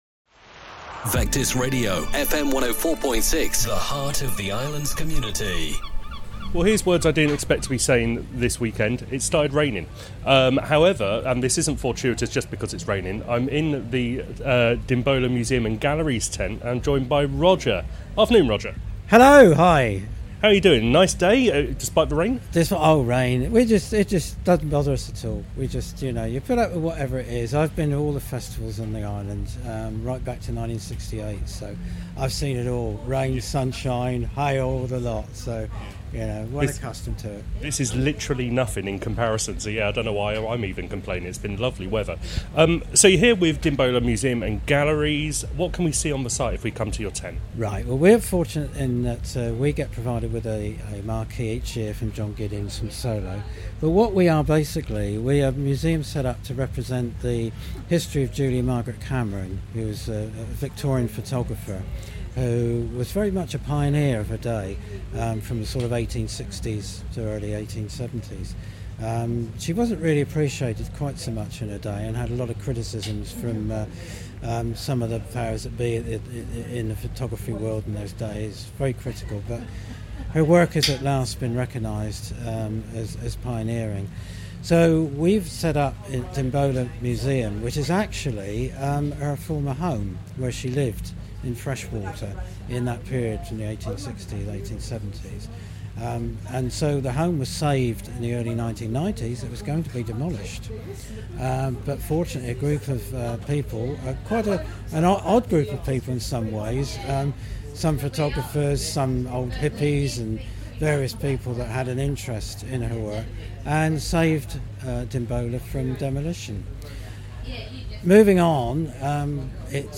headed to the Dimbola Museum and Galleries tent in Penny Lane